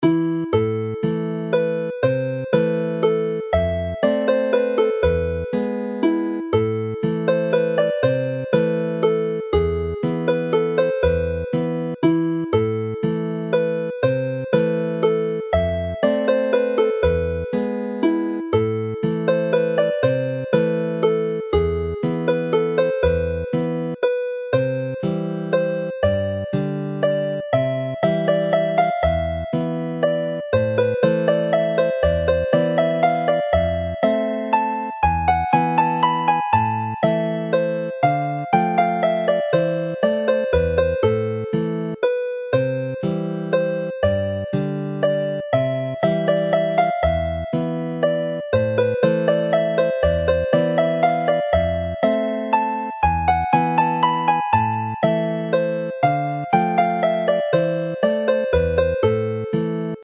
Alawon Cymreig - Set Blodau'r Drain- Welsh folk tunes to play
All three melodies are in the Welsh A minor which use a G# in the scale, giving an E major chord rather than the E minor generally found in Irish and Scottish tunes.